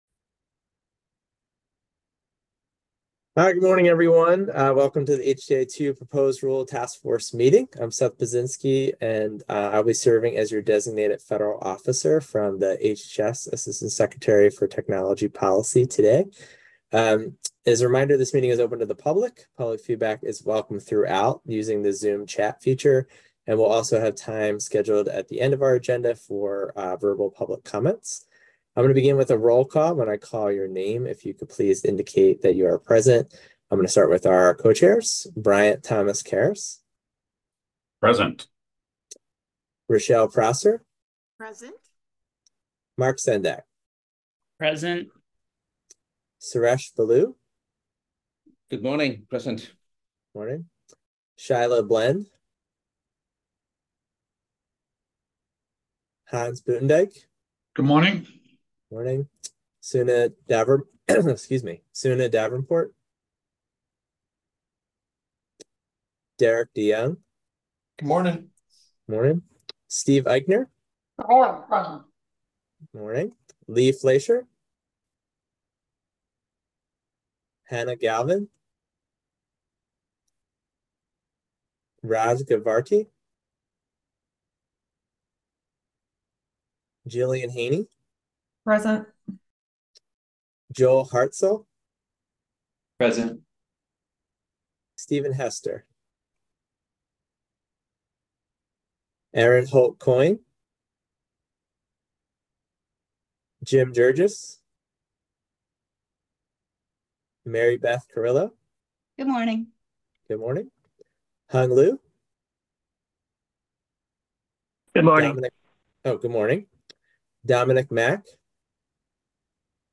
HTI-2 Proposed Rule Task Force Meeting Audio 9-3-2024